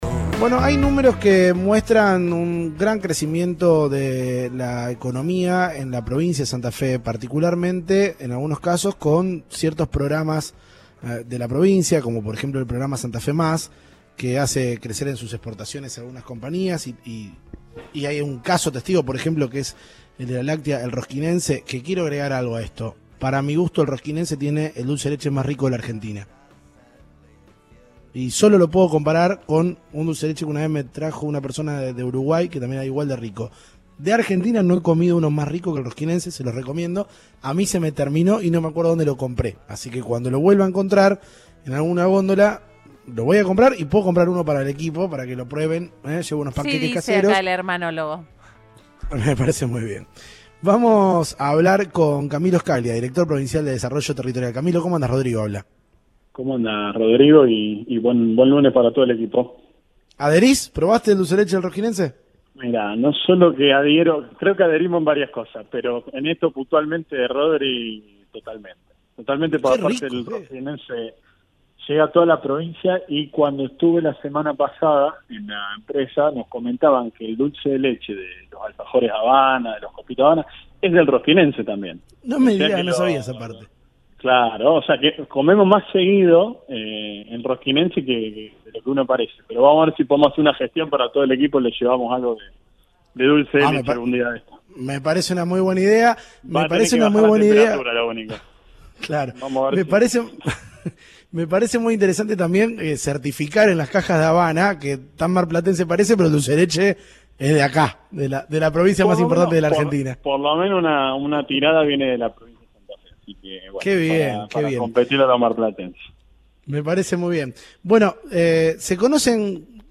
El director de Desarrollo Territorial de la provincia habló en Cadena 3 Rosario sobre la importancia que buscan darle a programas como el “Santa Fe Más”, que capacita junto a empresas y comunas.